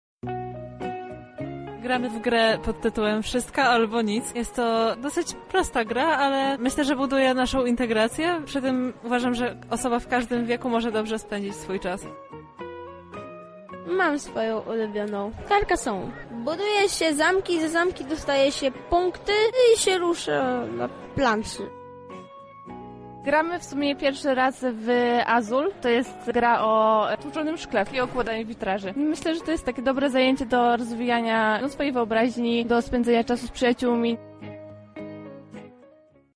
Gracze opowiedzieli o swoich zmaganiach.